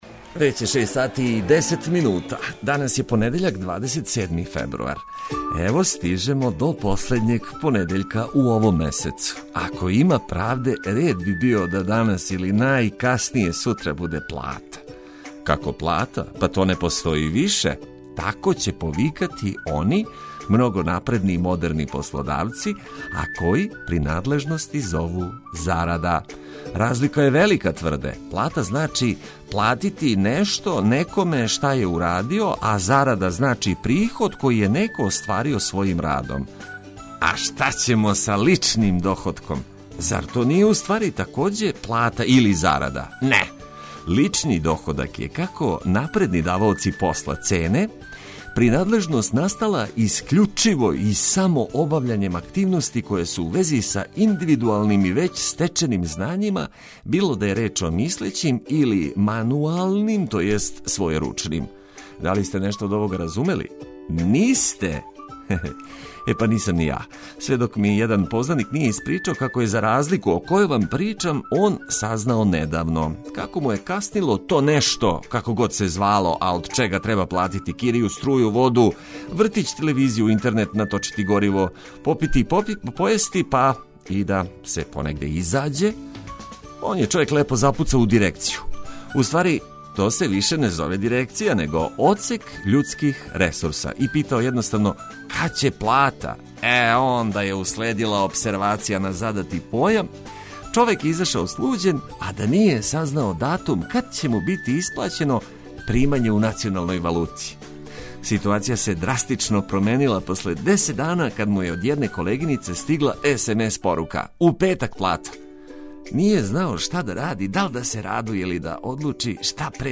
И овога јутра много корисних вести и ведре музике уз добро расположење за лакши почетак радне седмице.